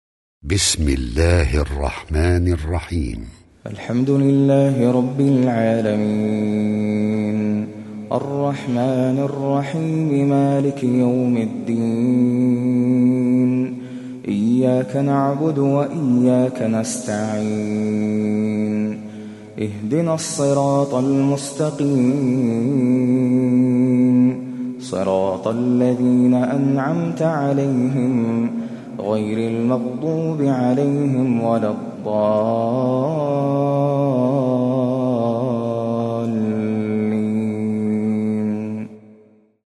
Его размеренное и спокойное чтение приятно на слух.
Слушать размеренное чтение сур